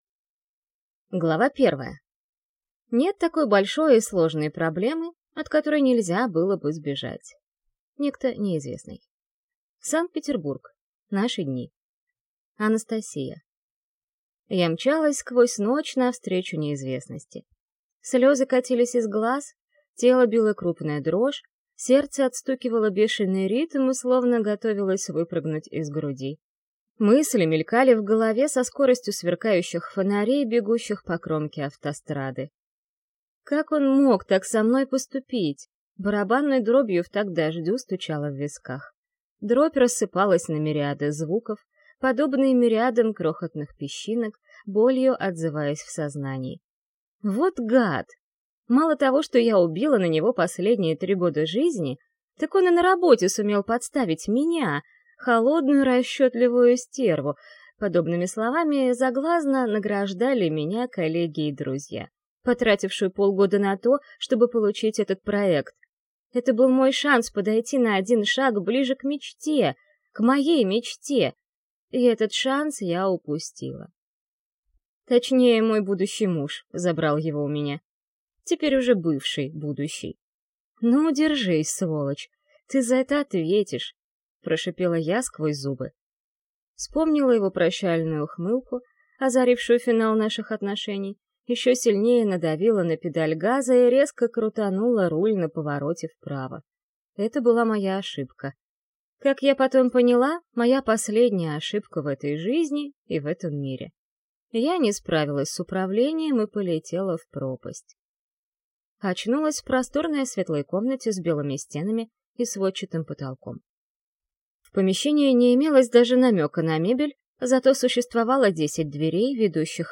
Аудиокнига Проклятие Владык | Библиотека аудиокниг